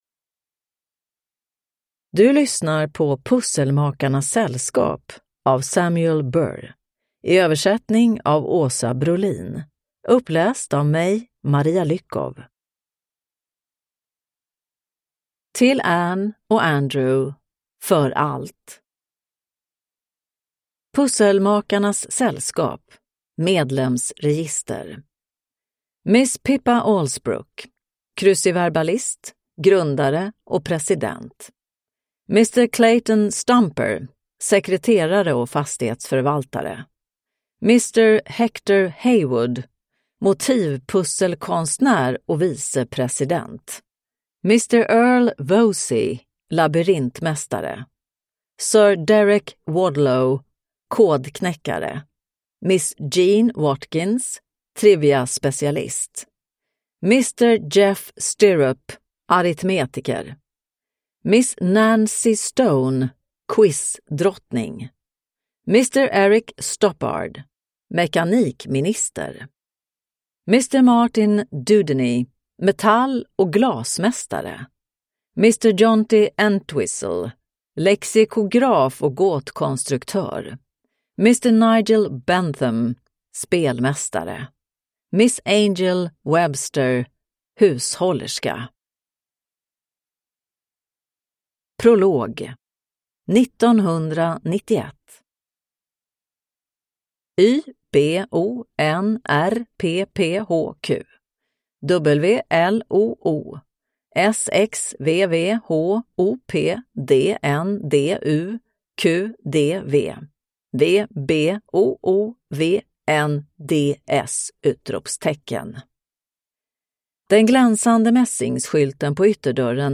Pusselmakarnas Sällskap (ljudbok) av Samuel Burr